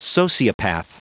Prononciation du mot sociopath en anglais (fichier audio)
Prononciation du mot : sociopath